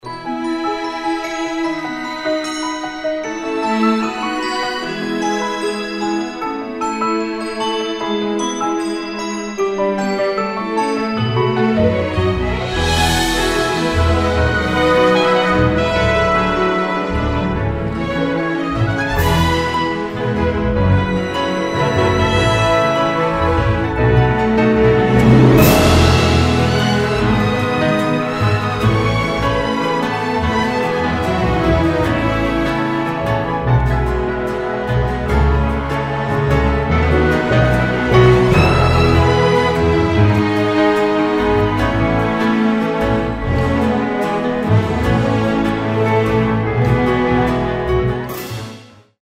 professional performance track
Instrumental